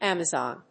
音節Am・a・zon 発音記号・読み方
/ˈæməzὰn(米国英語), ˈæmʌˌzɑ:n(英国英語)/